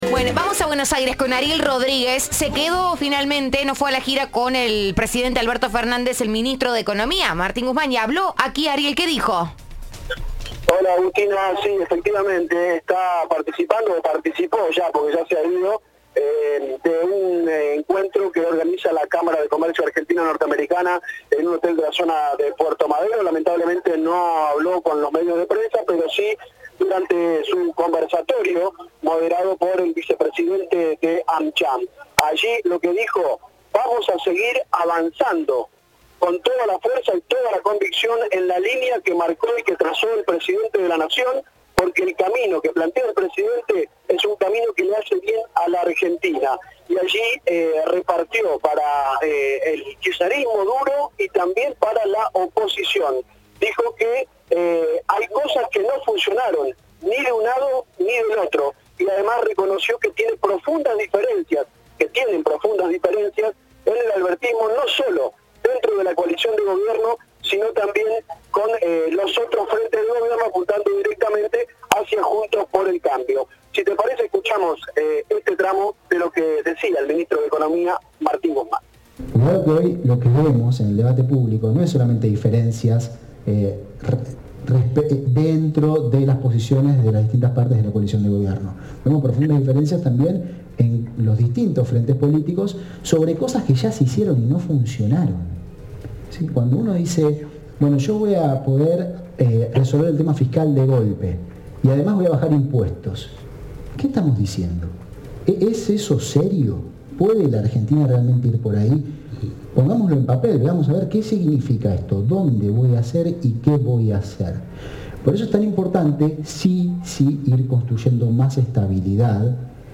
El ministro de Economía analizó la coyuntura del país al disertar en el foro de la Cámara de Comercio de Estados Unidos en la Argentina (AmCham).